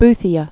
(bthē-ə)